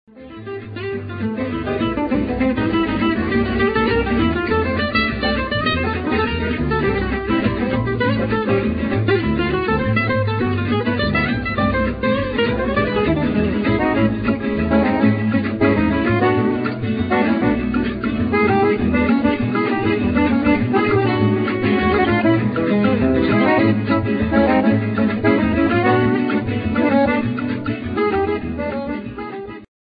Accordion